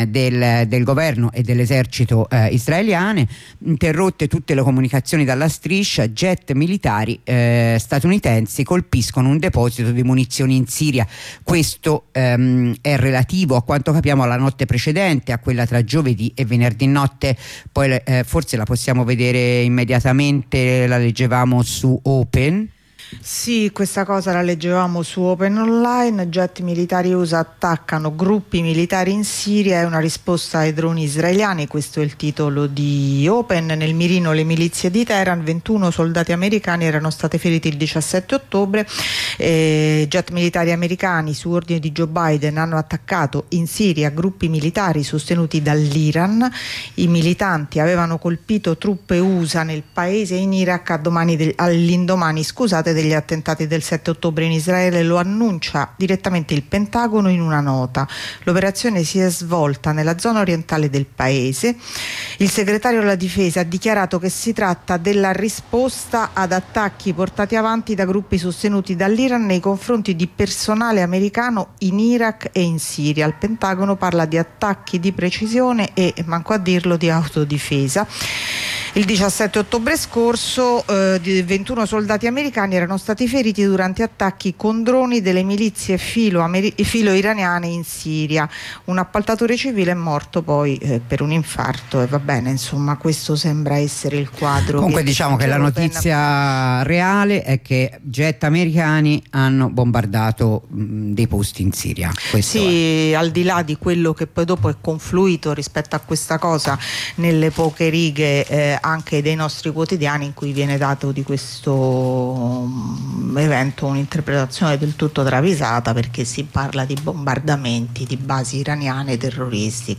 La rassegna stampa di sabato 28 ottobre